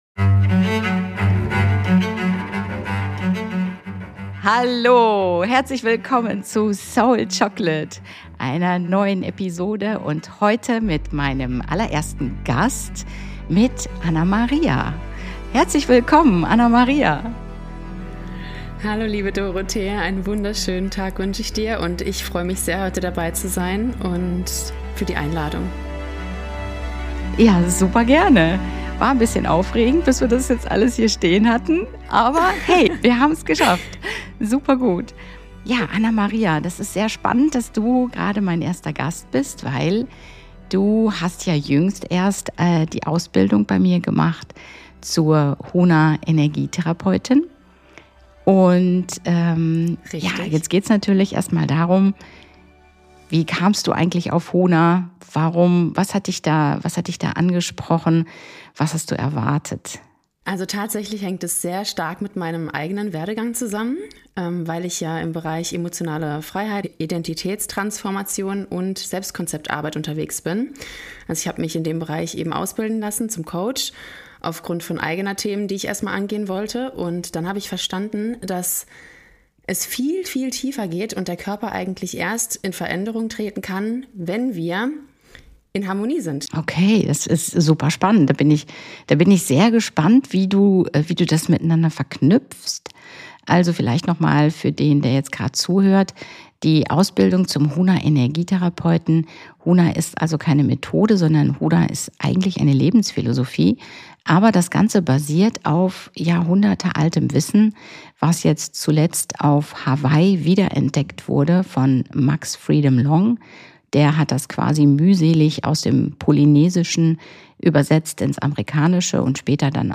Nr. 76 - Ich dachte, ich lerne Huna – stattdessen hat Huna mich gelehrt - Eine Teilnehmerin berichtet ~ Soul Chocolate - Dein Podcast für Bewusstseinserweiterung, Heilung & Medialität Podcast